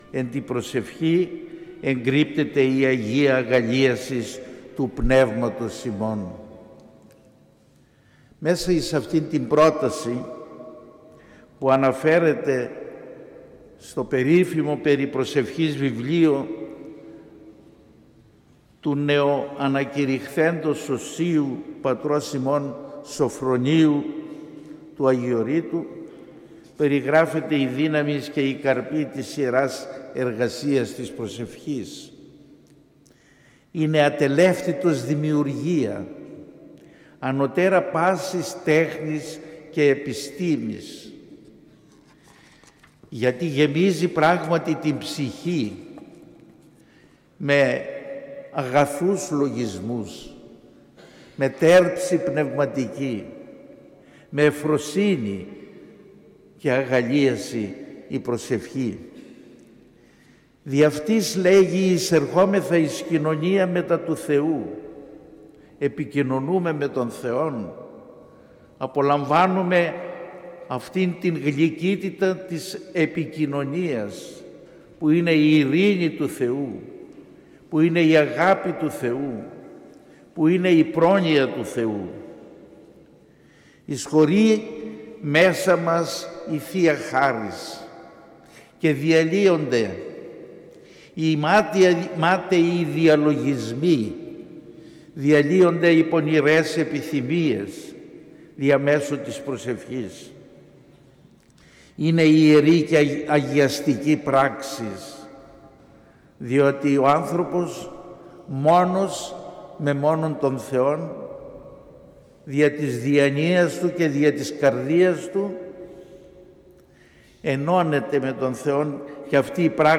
Μιὰ ψυχωφέλιμη ὀμιλία